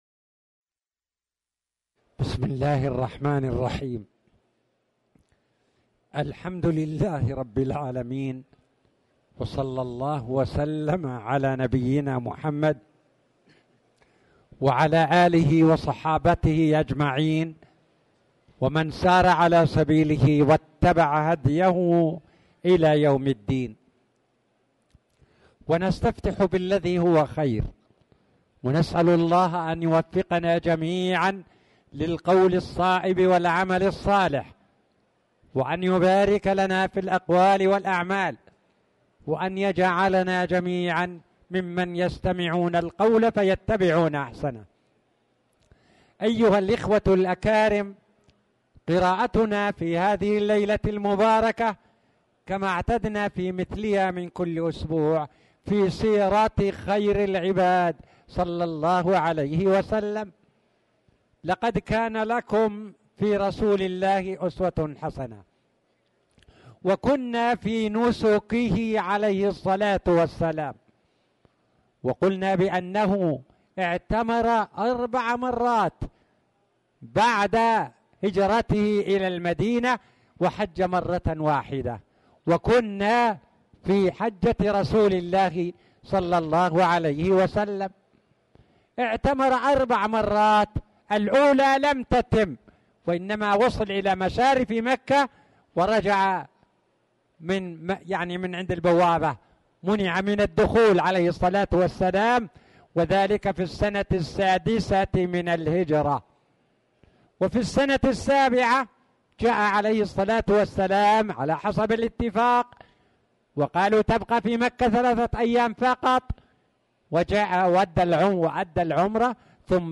تاريخ النشر ٣ صفر ١٤٣٨ هـ المكان: المسجد الحرام الشيخ